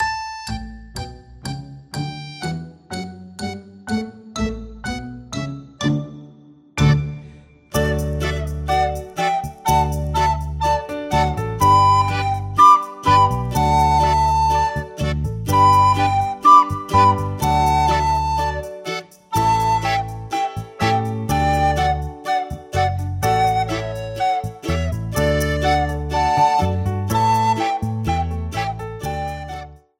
für eine oder zwei Sopranblockflöten
Besetzung: 1-2 Sopranblockflöten